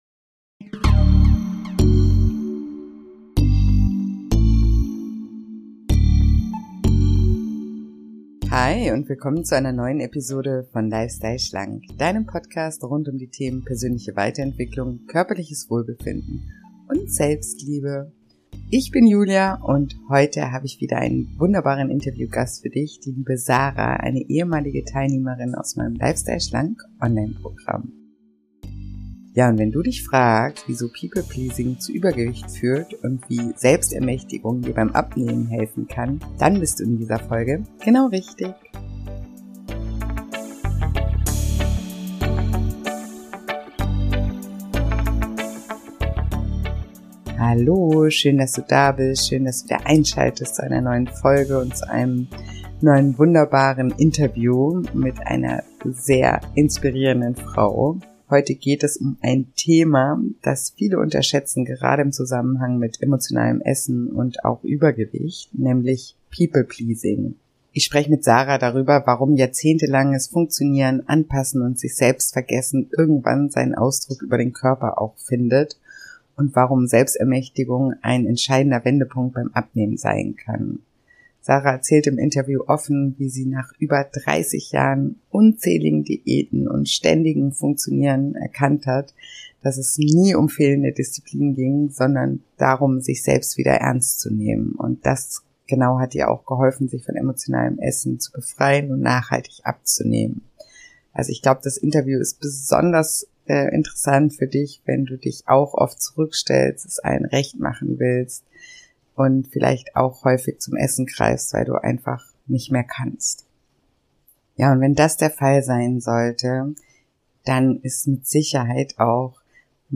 Ein sehr bewegendes und inspirierendes Interview, das du nicht verpassen solltest.